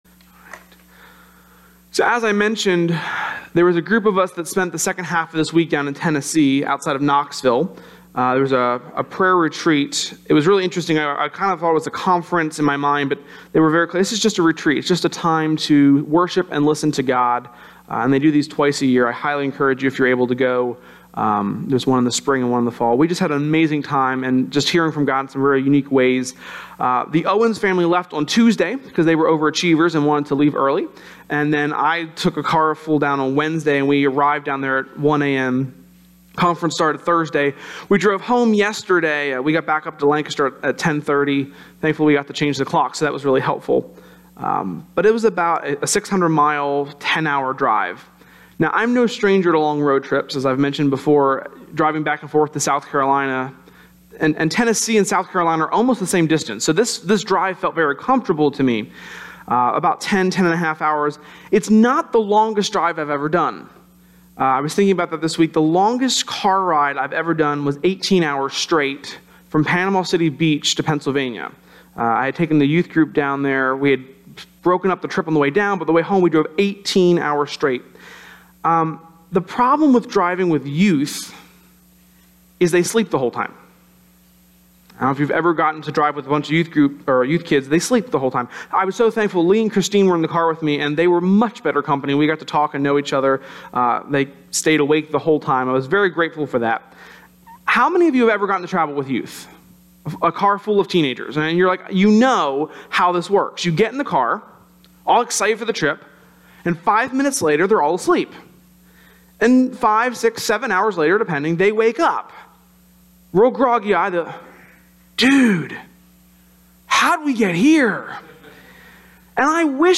Sermon-11.5.17.mp3